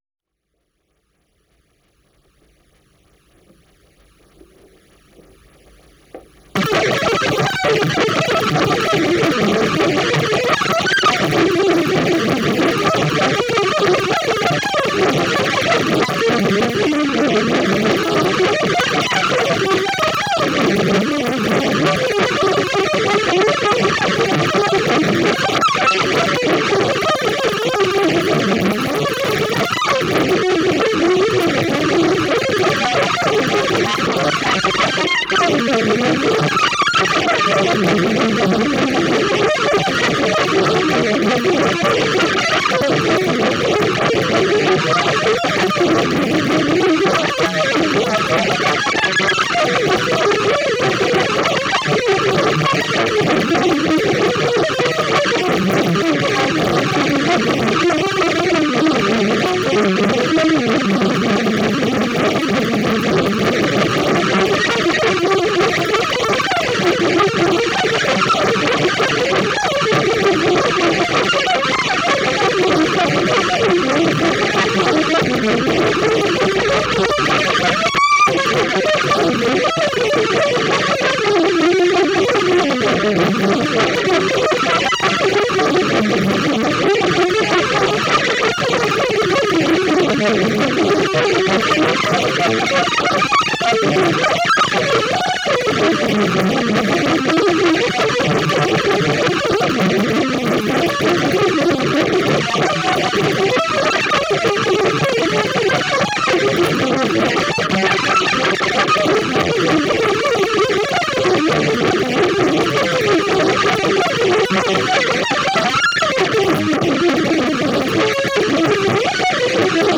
美しく爆発的に拡散飛翔する水晶の欠片のような極微細音群。
これはディストーションとパラメトリック・イコライザーが搭載されたペダルです。
演奏録音後に施された特殊なステレオ音場（空間）処理以外には、編集もオーバーダビングもおこなわれていません。
演奏直前の緊張感も良い感じなので、敢えて第1音を弾き始める直前の数秒間も収録されています。
ギター愛好家の方々にはもちろん、現代音楽、先端的テクノ、実験音楽をお好きな方々にもお薦めのアルバムです。